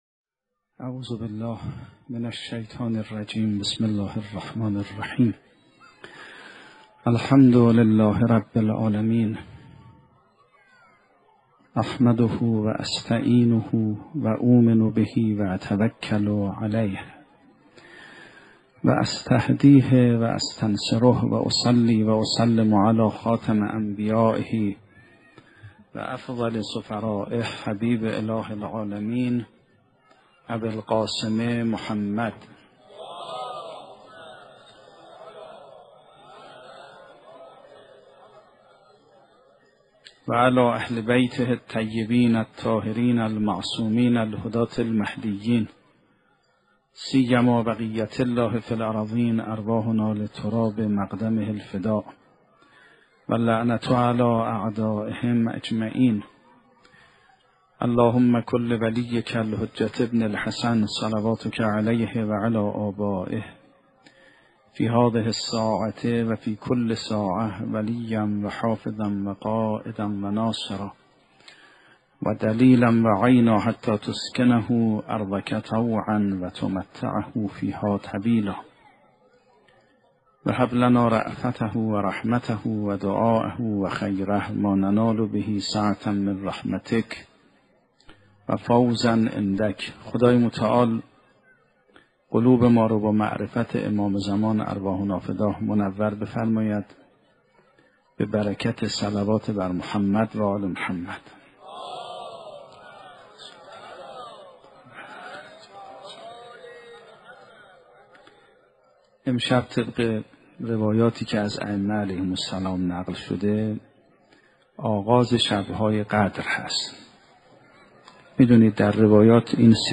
سخنرانی آیت الله سیدمحمدمهدی میرباقری با موضوع شب قدر، شب رسیدن به امام زمان و رسیدن به مقام اخلاص و محبت